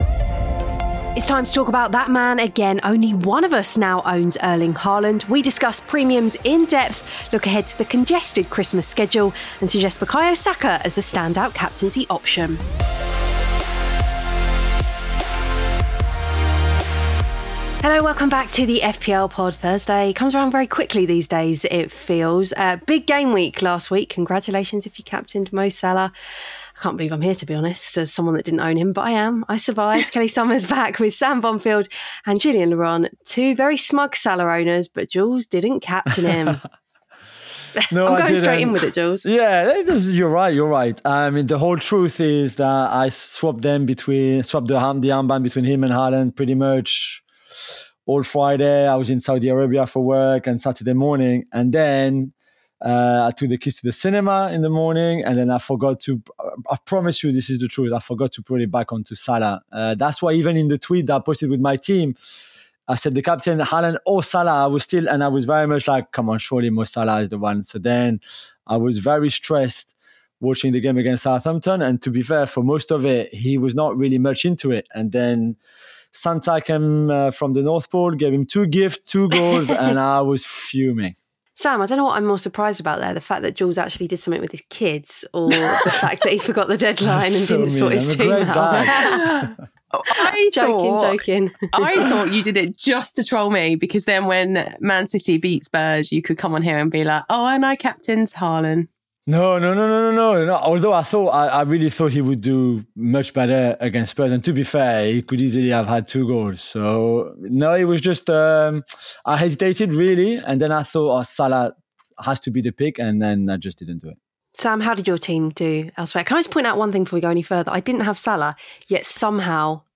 After each Gameweek, two Fantasy Premier League experts will discuss all the major talking points and analyse the key decisions in Off the Bench.